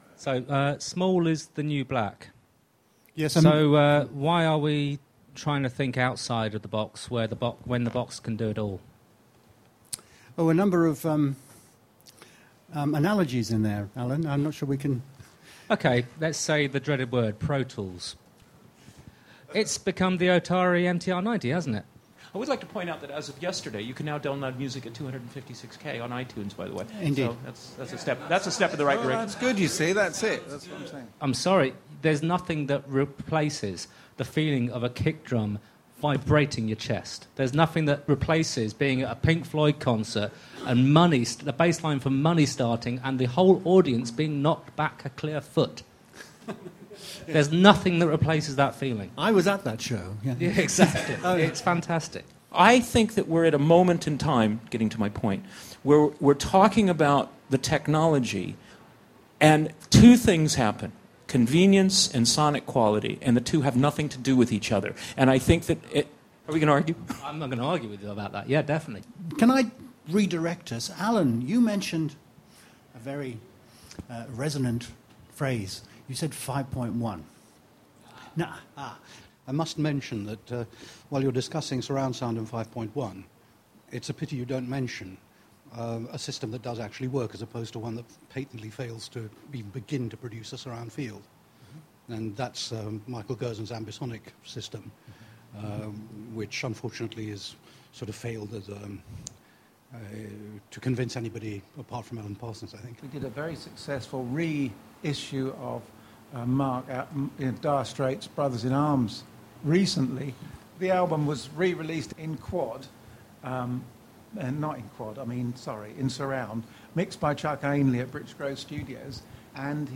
Once again we took over the upstairs function room at The Bath House, this time for an "Open Mic" evening.